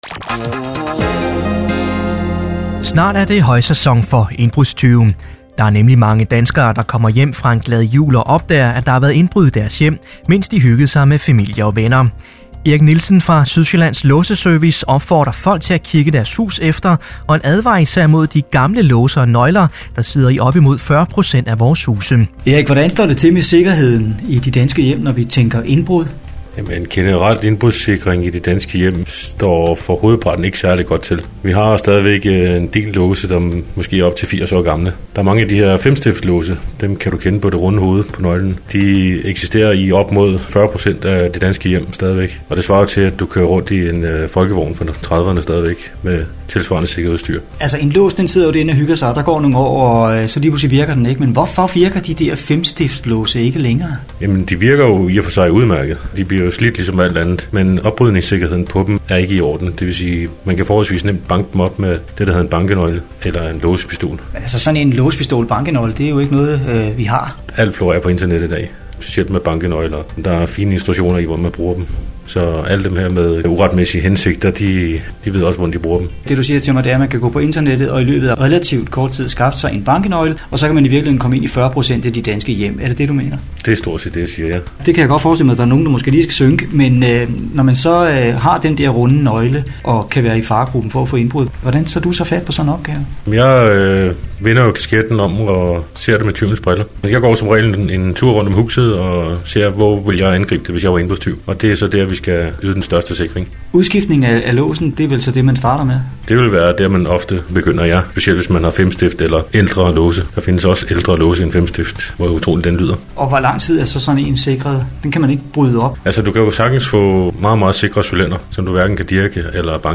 Sydsjaellands-Låseservice-nov-2011-interview.mp3